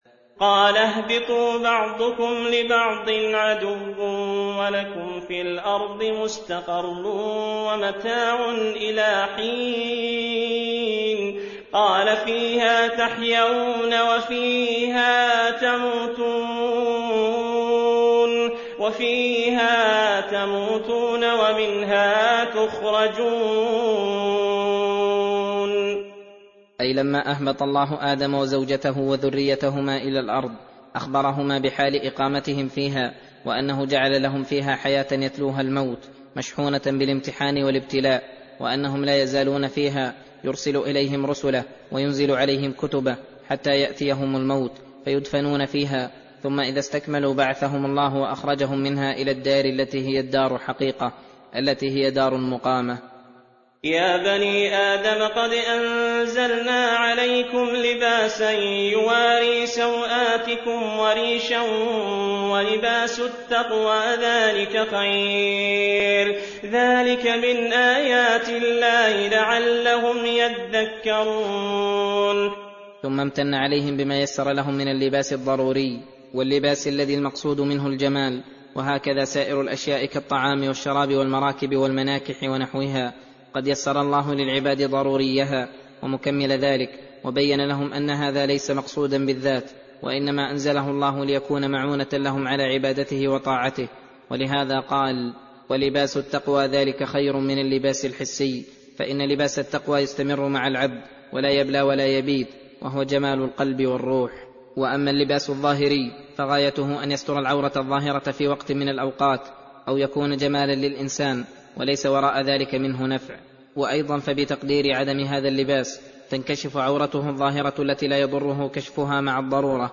درس (2) : تفسير سورة الأعراف : (24-41)